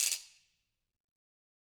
Ratchet1-Crank_v1_rr2_Sum.wav